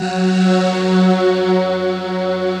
Index of /90_sSampleCDs/Optical Media International - Sonic Images Library/SI1_Breath Choir/SI1_BreathMellow